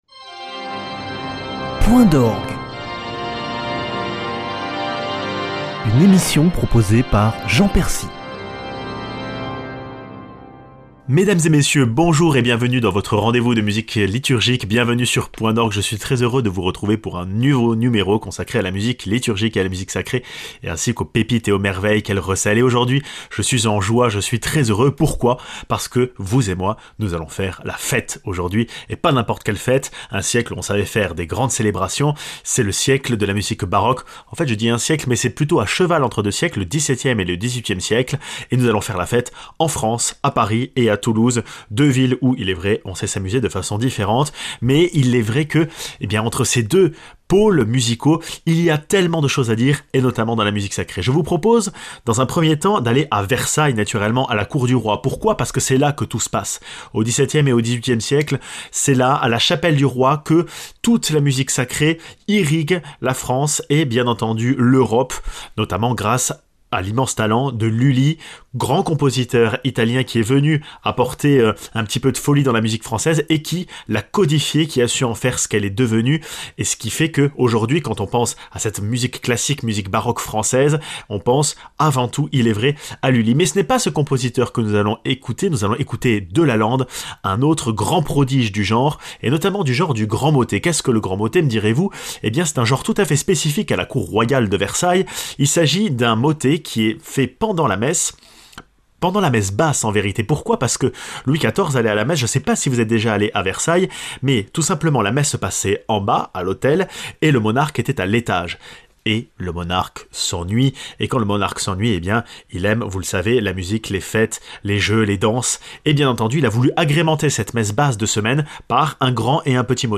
[ Rediffusion ] Période fastueuse entre toutes, la musique sacrée baroque possède quelques surprises festives (Te Deum de Delalande , Motets de Valette de Montigny)